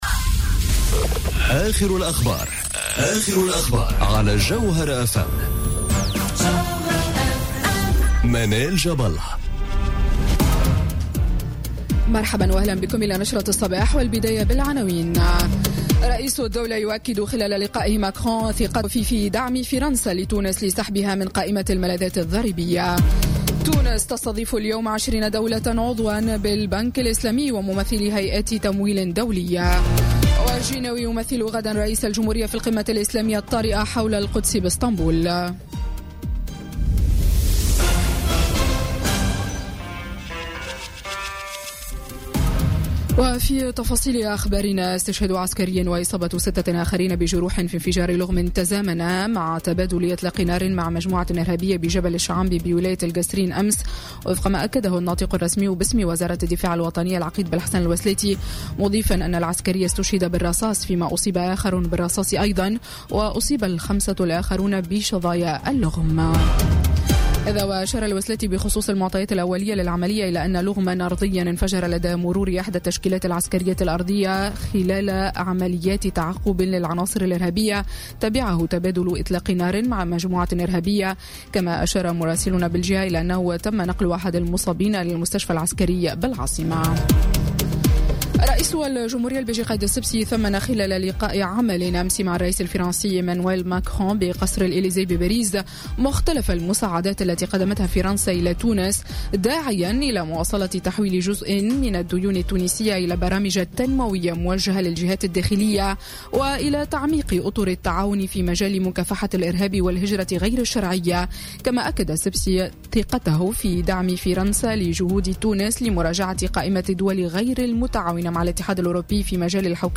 نشرة أخبار السابعة صباحا ليوم الثلاثاء 12 ديسمبر 2017